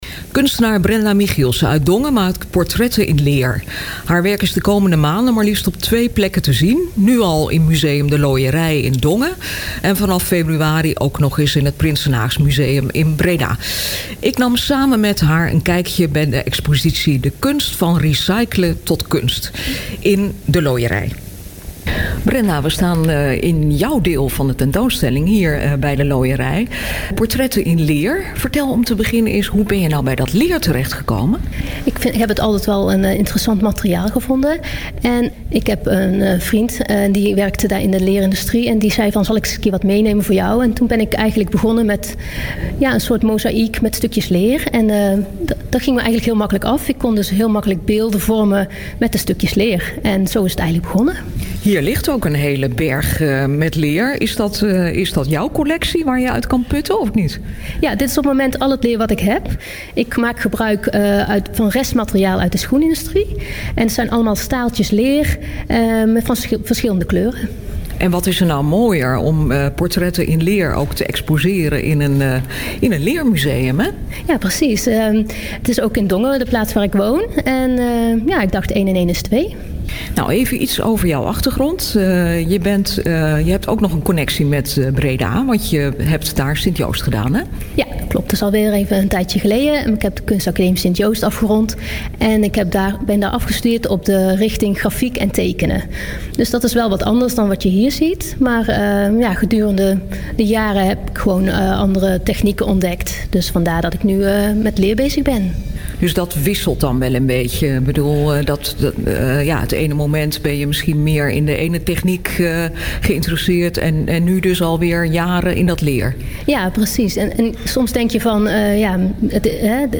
Druk op de knop hiernaast voor een interview uit het programma Grensgeluiden van BredaNu.